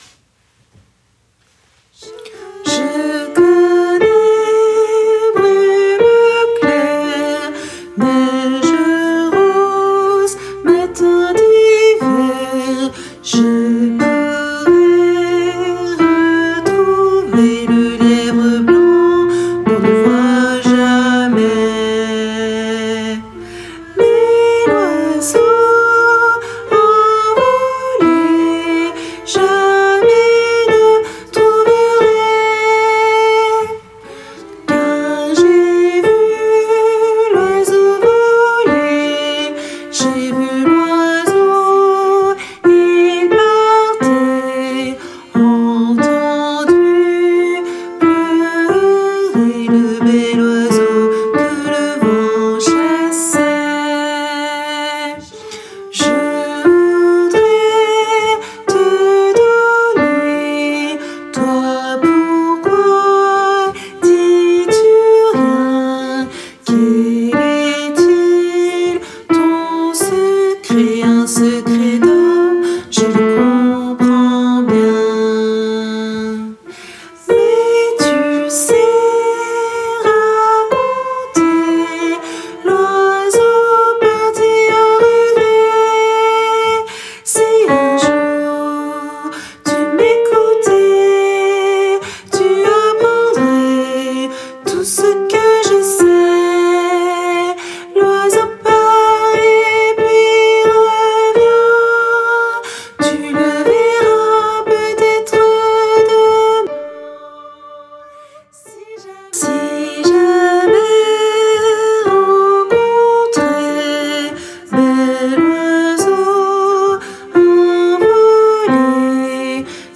Basse et autres voix en arrière-plan